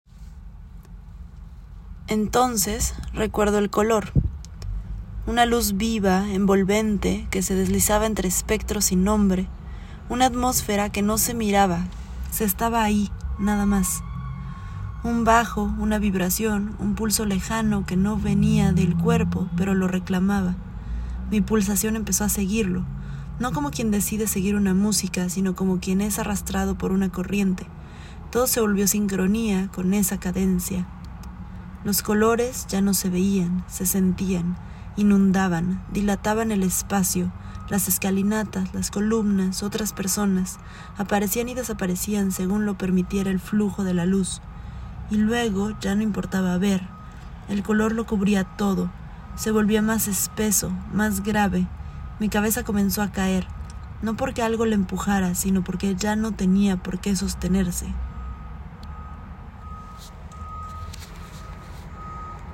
Cada fragmento fue escrito como un flujo de conciencia, grabado en audio con mi voz y acompañado por imágenes específicas, evocadas directamente por lo que se cuenta o añadidas por asociación libre.